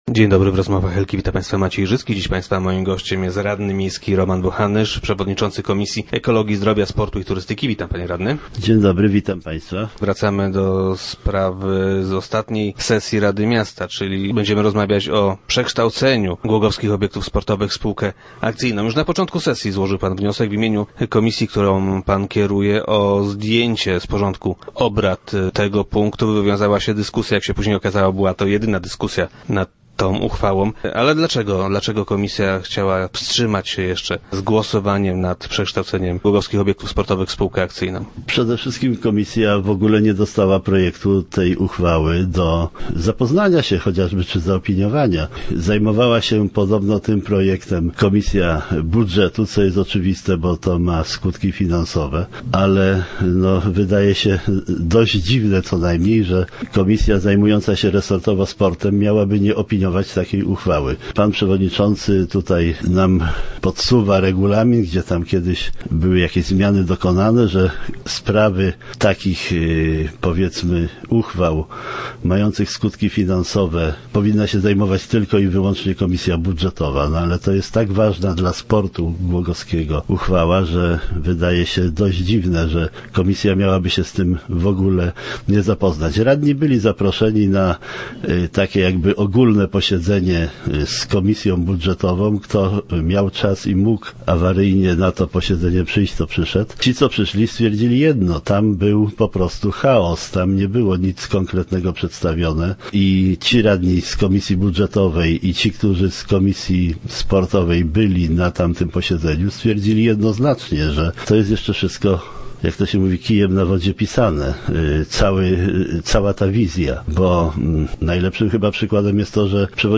0401_bochanysz.jpgZdaniem przewodniczącego komisji ekologi, zdrowia, sportu i turystyki decyzja o wyrażeniu zgody na przekształcenie Głogowskich Obiektów Sportowych w spółkę akcyjną, podjęta została zbyt pochopnie. Roman Bochanysz był gościem piątkowych Rozmów Elki.